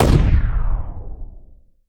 poly_explosion_medium.wav